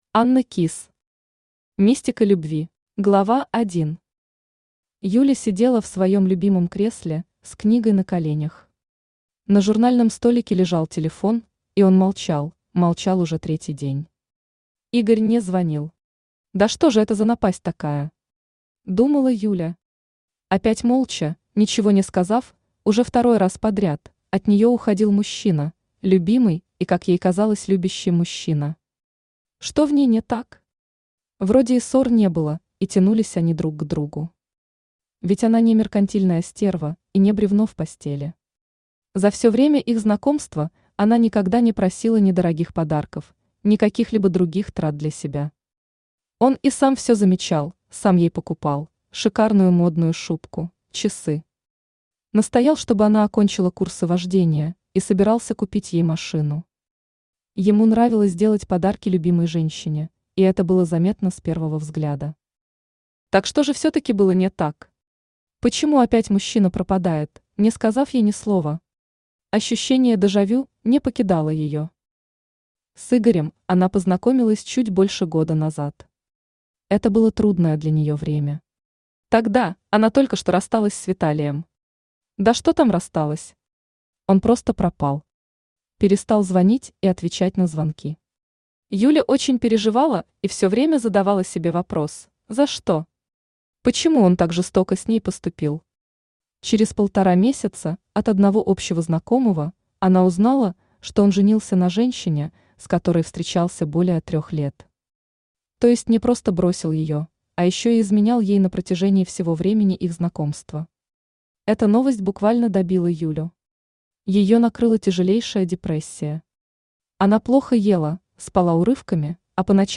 Аудиокнига Мистика любви | Библиотека аудиокниг
Aудиокнига Мистика любви Автор Анна Кисс Читает аудиокнигу Авточтец ЛитРес.